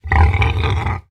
Minecraft Version Minecraft Version snapshot Latest Release | Latest Snapshot snapshot / assets / minecraft / sounds / mob / hoglin / retreat2.ogg Compare With Compare With Latest Release | Latest Snapshot